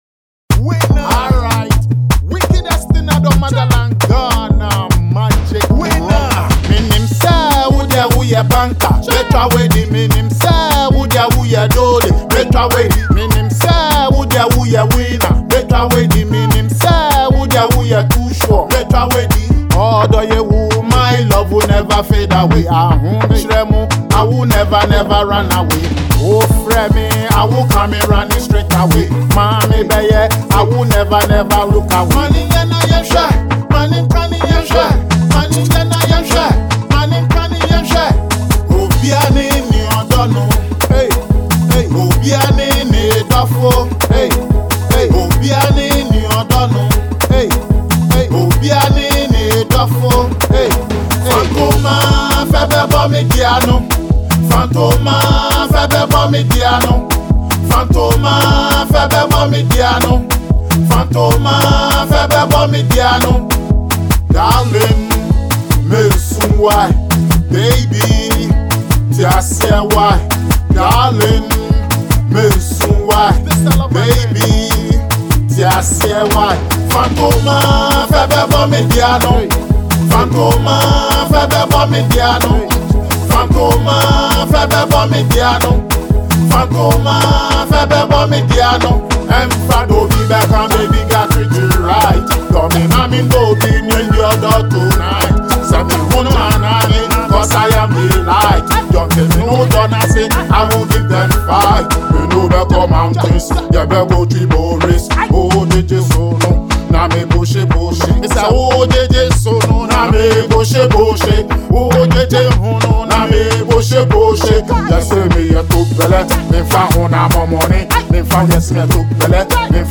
love song
would keep you on the dance floor over the weekend